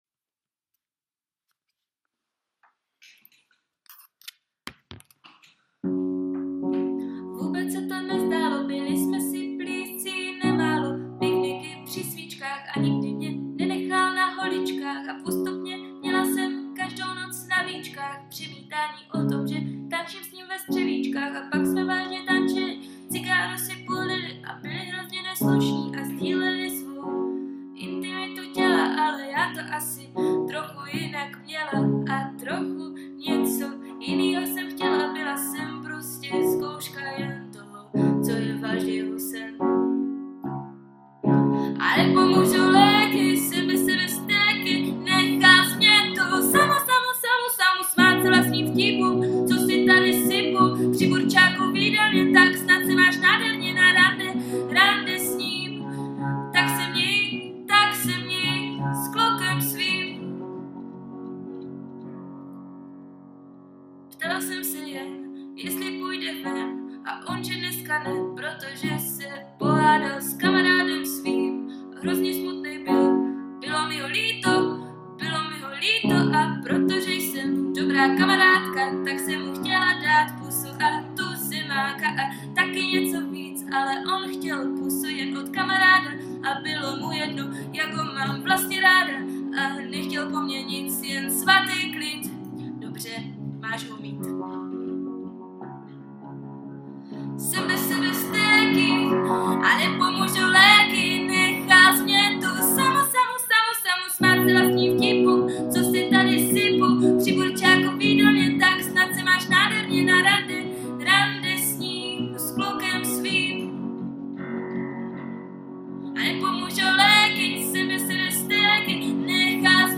kategorie ostatní/písně